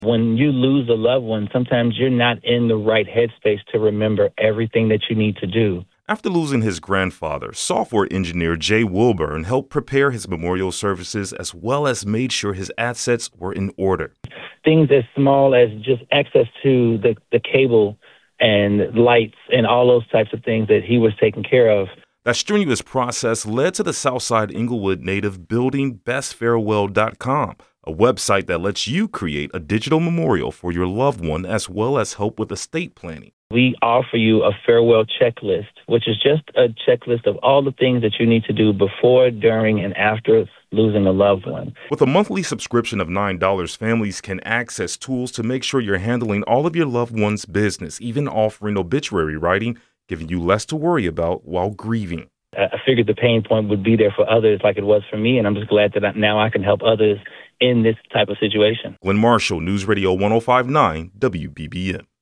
A dive into the top headlines in Chicago, delivering the news you need in 10 minutes or less multiple times a day from WBBM Newsradio.